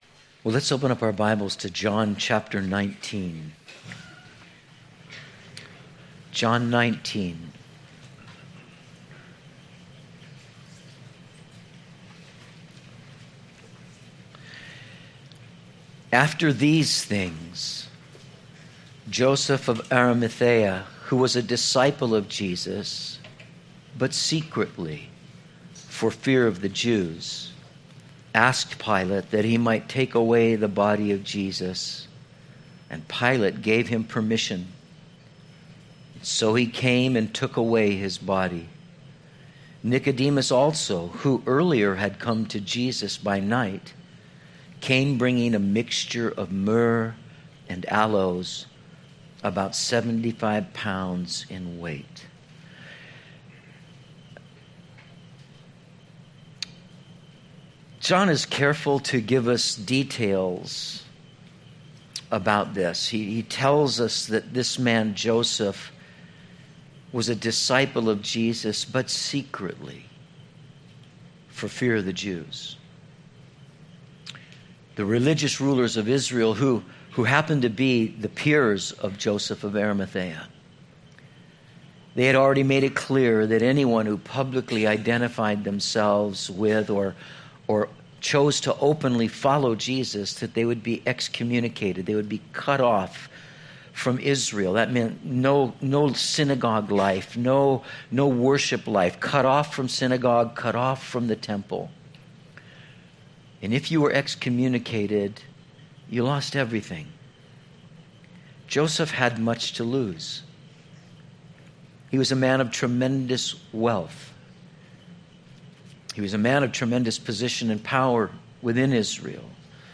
03/25/16 The Day Jesus Died - Metro Calvary Sermons